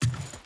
Footsteps / Wood / Wood Chain Jump.wav
Wood Chain Jump.wav